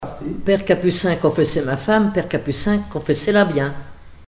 formulette enfantine : amusette
comptines et formulettes enfantines
Pièce musicale inédite